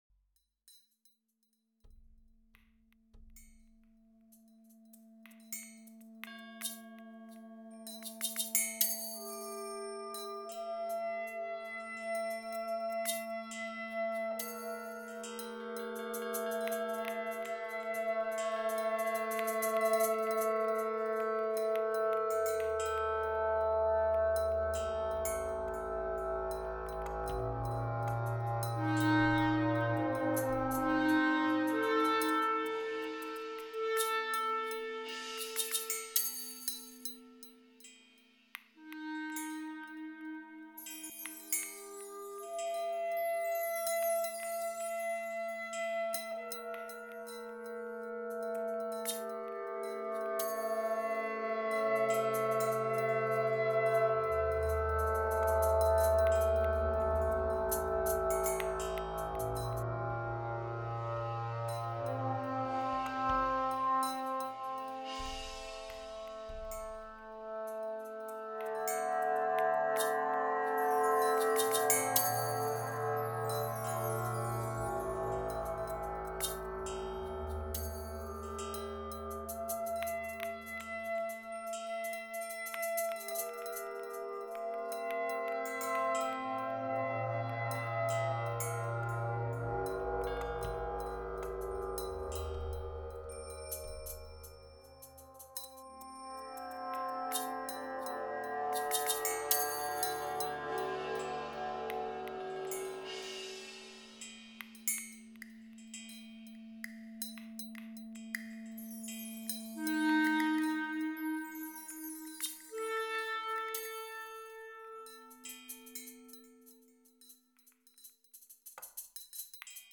Les 3_8 au Moulin de Constance
Une chouette discrète, un rossignol acharné, des chauves-souris empressées et tout un monde nocturne invisible et silencieux.